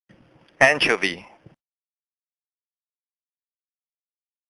老美都是這麼唸！
這才是道地的美式發音喔！
注意重音要擺在第一個音節，而不是第二個音節。